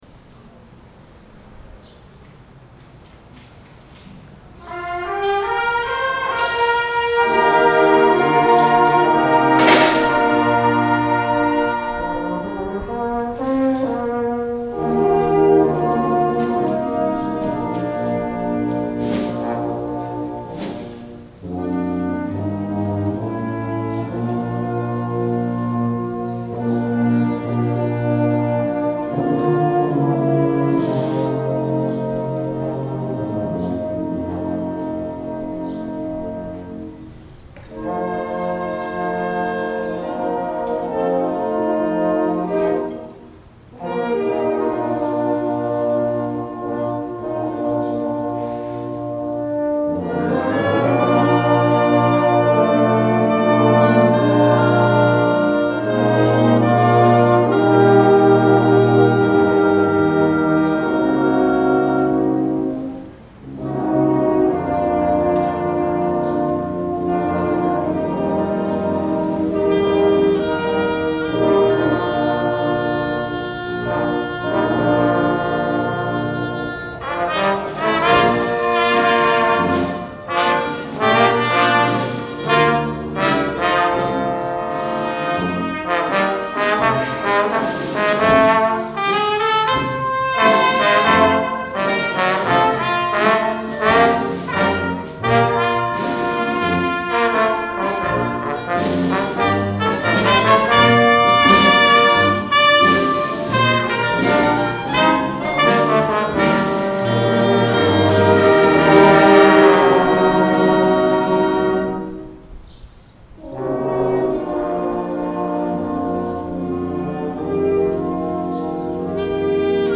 Concert Band
Recorded at the Prince William County Band Festival – May 22, 2022